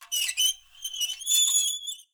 574 Metallic Squeaking 03 2s 0.03 MB